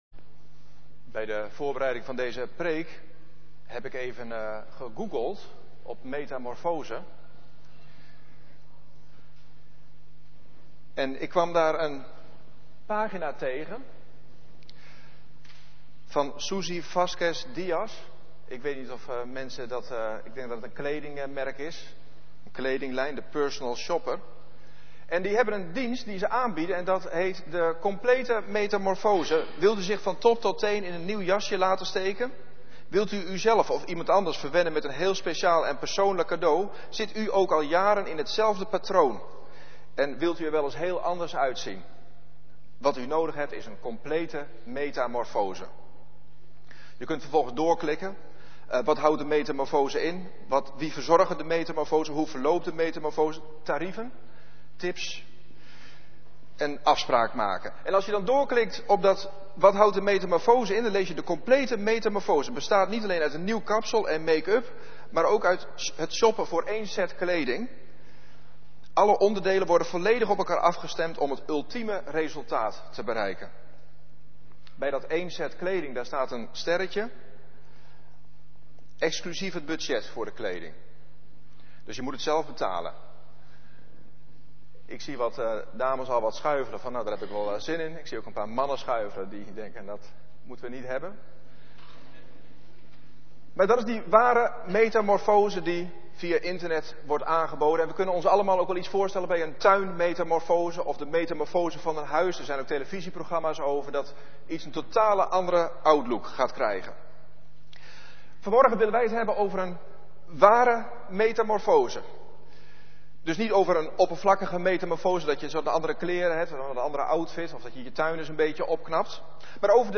Preek 1: Oh, wat mooi! Luisterpreek (28’27”) | Leespreek | Presentatie | Bronwater (preeksamenvatting) Preek 2: Kijk, kijk!